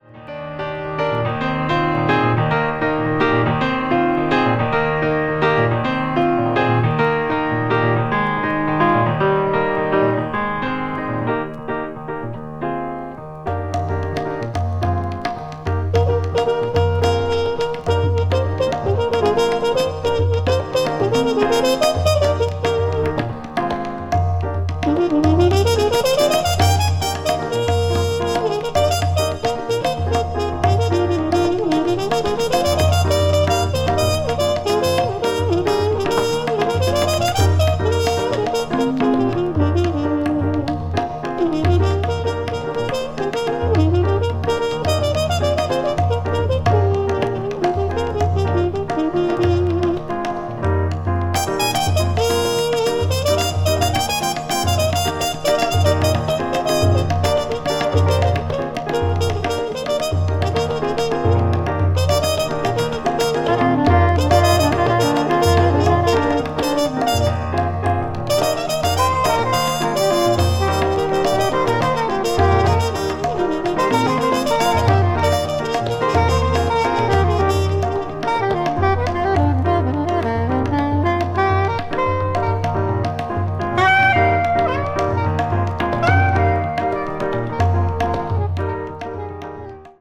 contemporary jazz   deep jazz   ethnic jazz   free jazz